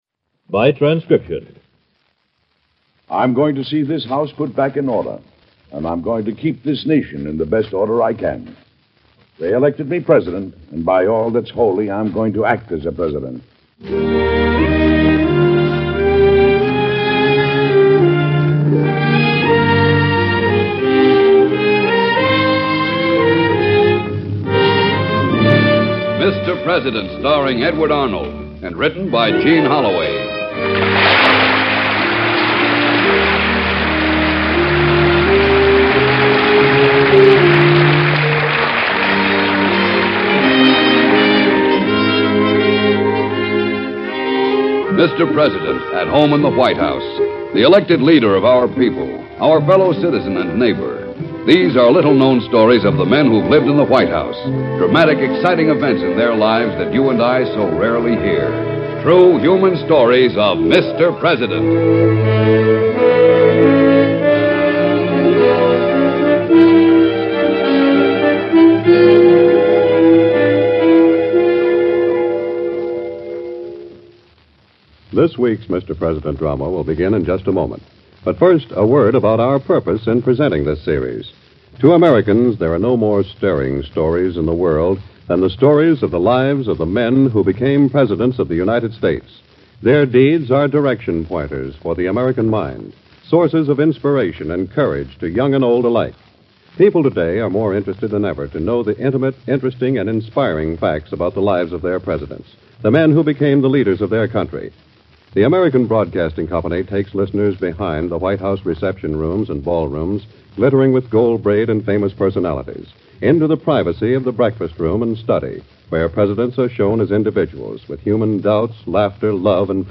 Mr. President, Starring Edward Arnold